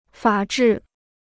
法制 (fǎ zhì) Free Chinese Dictionary